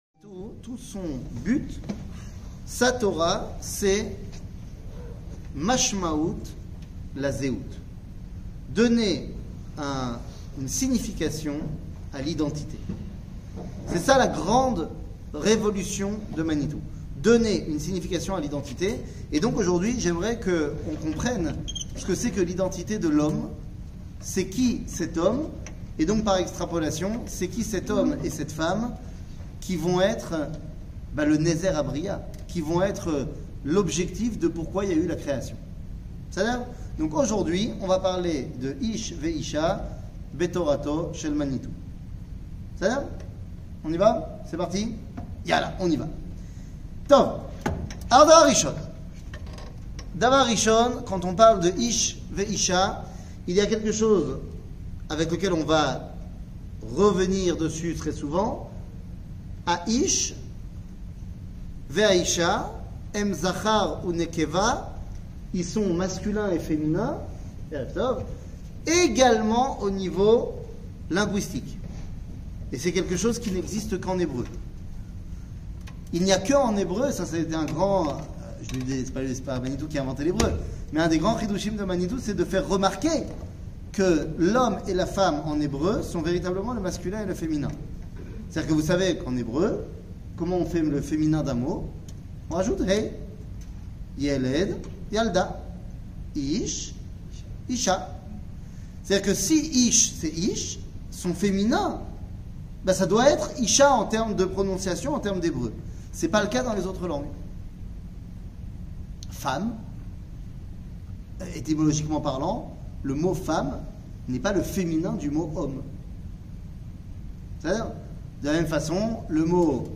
שיעורים, הרצאות, וידאו
שיעורים קצרים